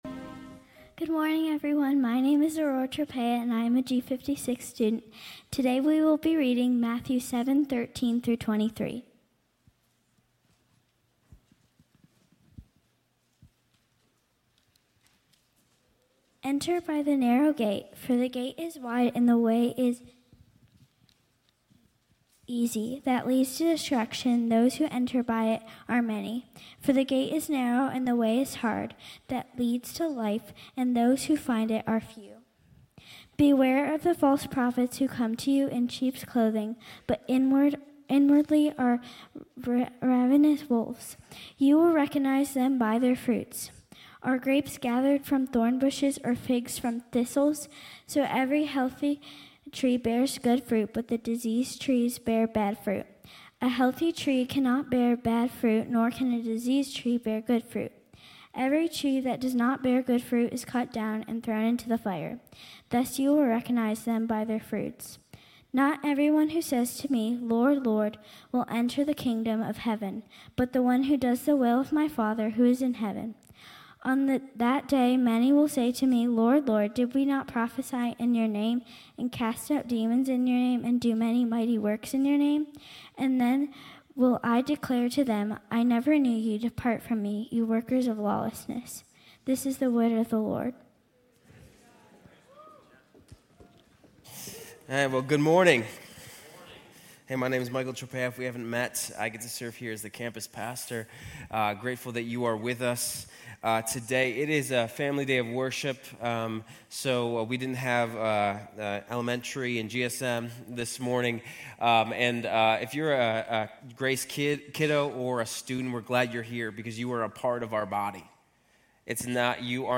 Grace Community Church University Blvd Campus Sermons 3_30 University Blvd Campus Mar 31 2025 | 00:32:27 1x 00:00 / 00:32:27 Subscribe Share RSS Feed Share Link Embed